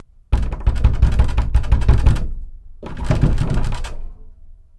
جلوه های صوتی
دانلود صدای در چوبی 4 از ساعد نیوز با لینک مستقیم و کیفیت بالا
برچسب: دانلود آهنگ های افکت صوتی اشیاء دانلود آلبوم صدای کوبیدن در چوبی از افکت صوتی اشیاء